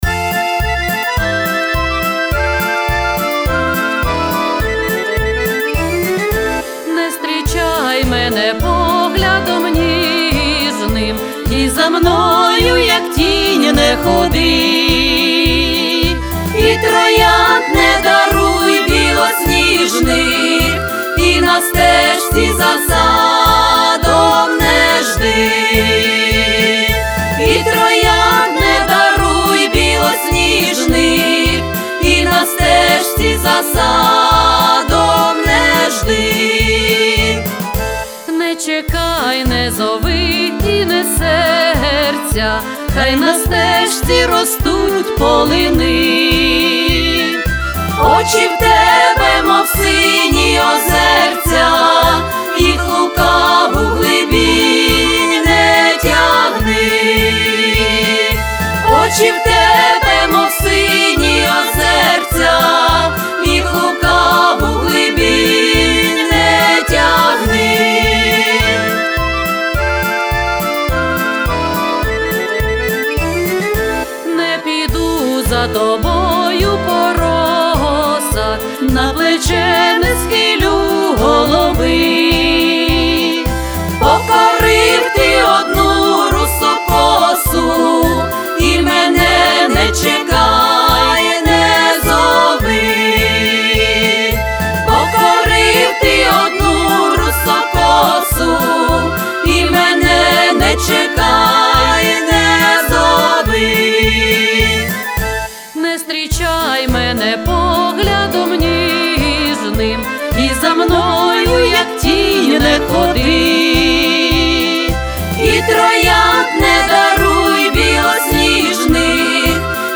Всі мінусовки жанру Folklore
Плюсовий запис